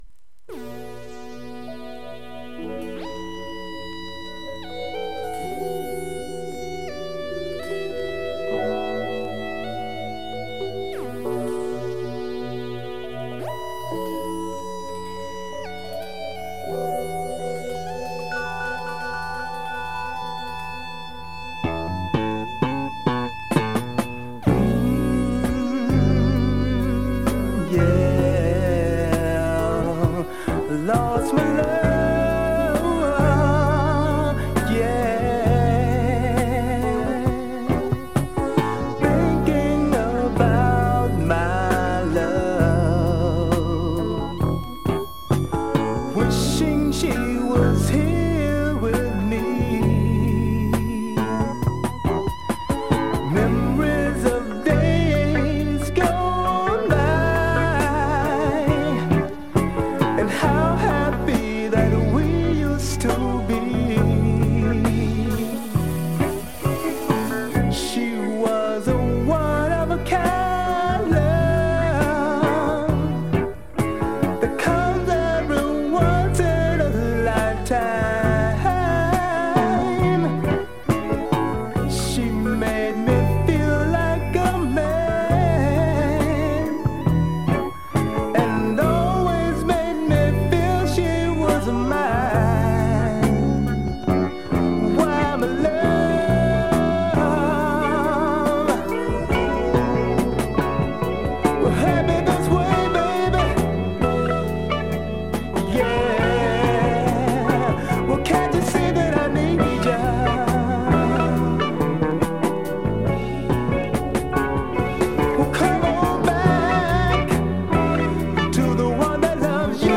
スペーシー・ファンク・バラード
◇小キズが少々あり曲によってプツ音目立つ箇所あります